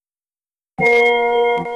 Windows NT 1.01-2.1 Startup & Shutdown.wav